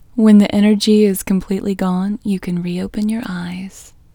OUT Technique Female English 33